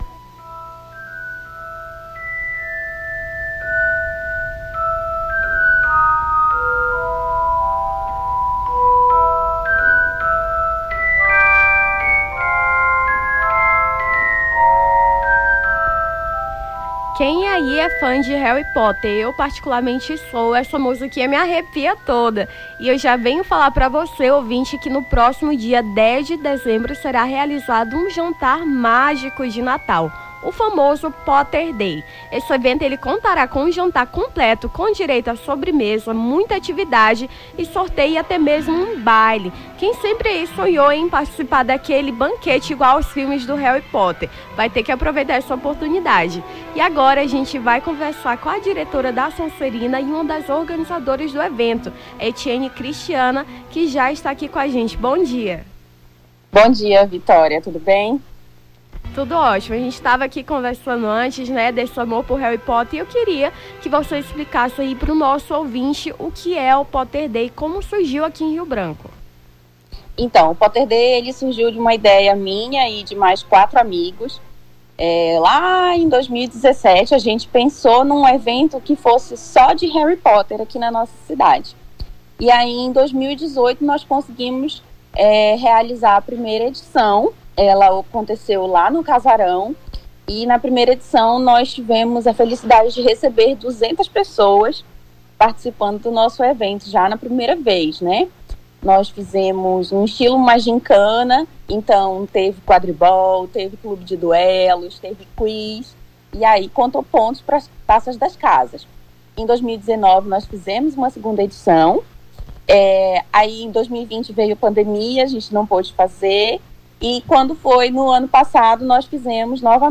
Nome do Artista - CENSURA - ENTREVISTA (POTTERDAY) 27-11-23.mp3